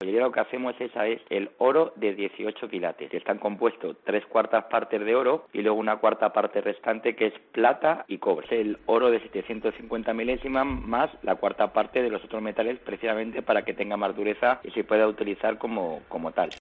joyero de Madrid con más de 30 años de profesión